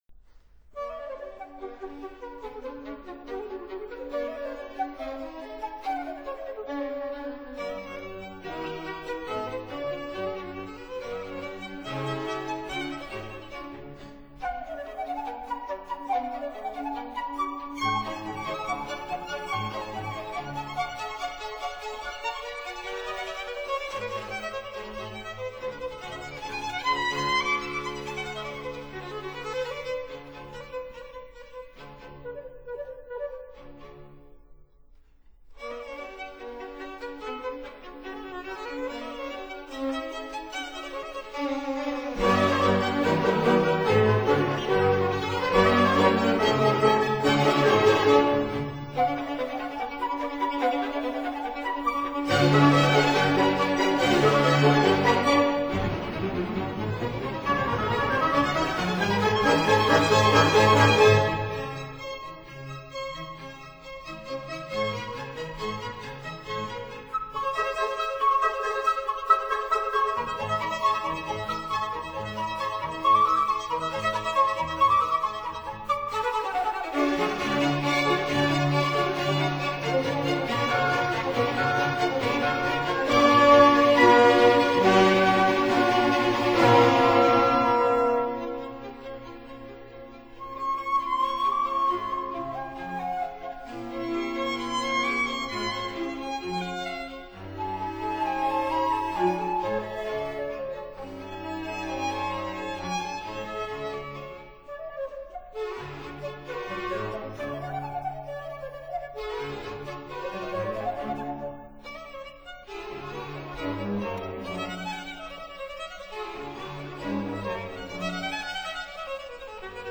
類型： 古典音樂
Flute
Violin
Cellos
(Period Instruments)